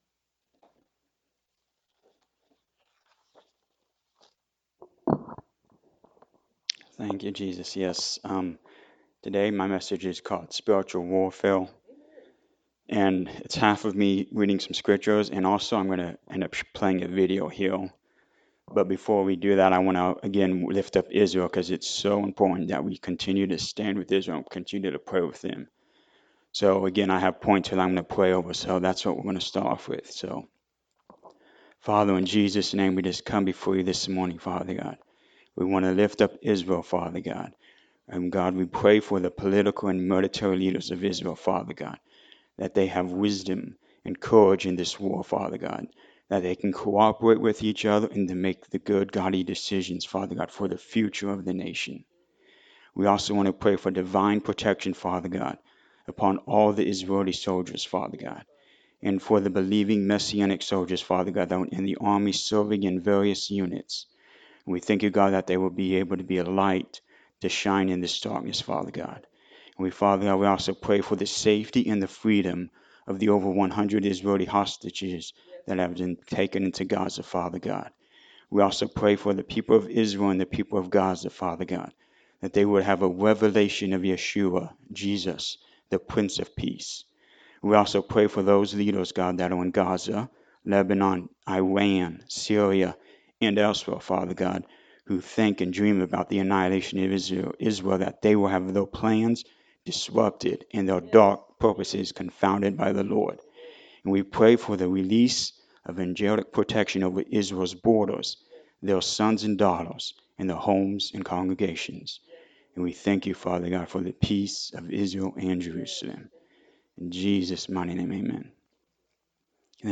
Battling the Darkness Service Type: Sunday Morning Service What has happened to Israel is astronomical.